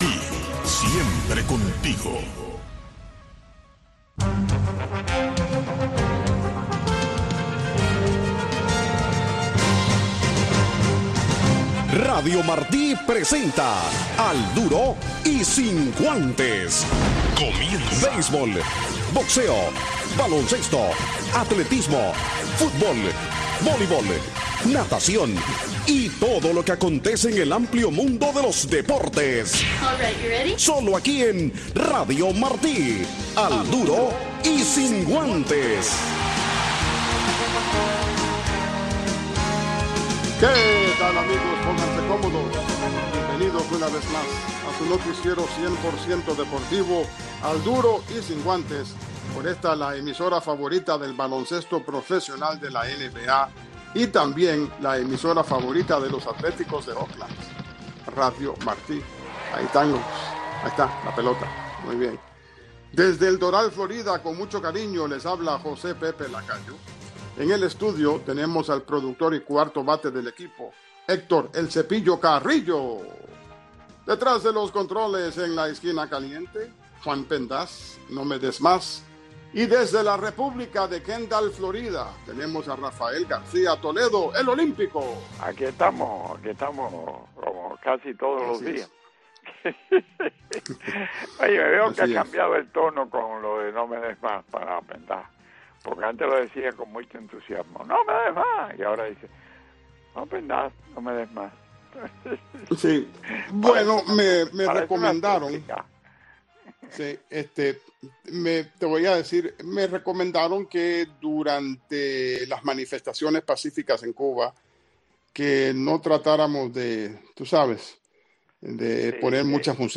Noticiero Deportivo conducido por